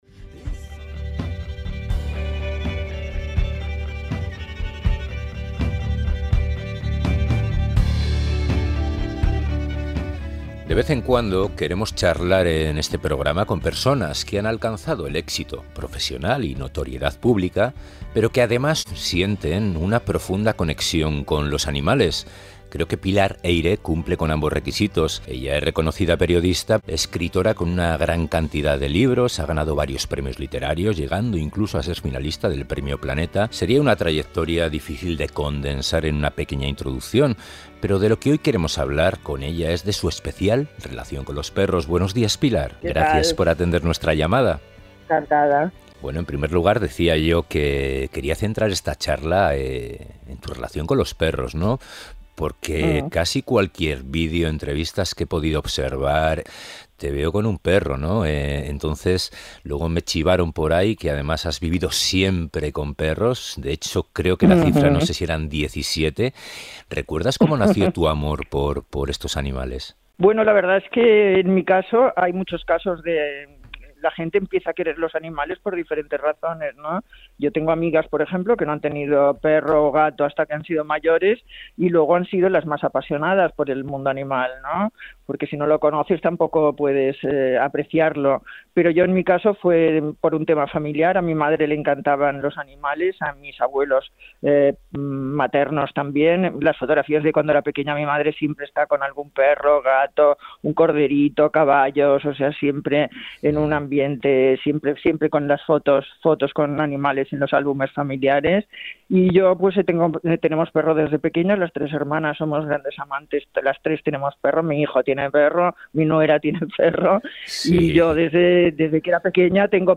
Escucha el podcast Pilar Eyre nos habla sobre su entrañable y especial relación con los perros y disfruta con los mejores momentos de Café con Patas en su sección Entrevista...